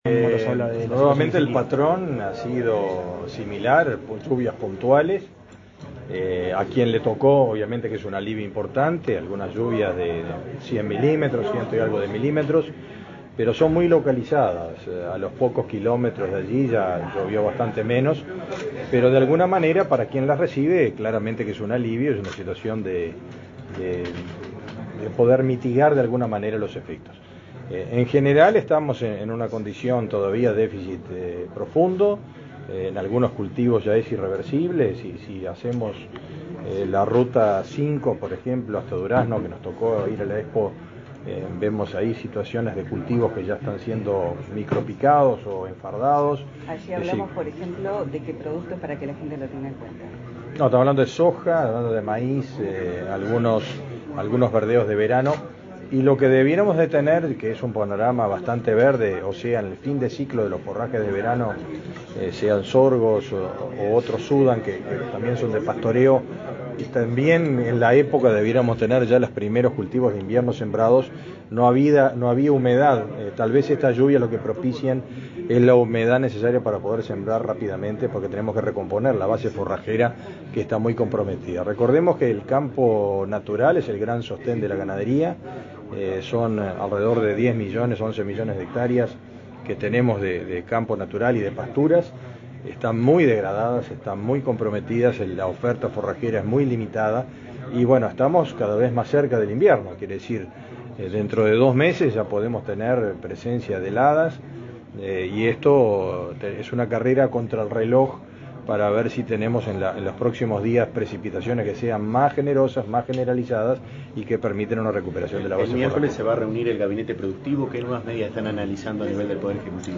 Declaraciones del ministro de Ganadería, Fernando Mattos
Luego, dialogó con la prensa.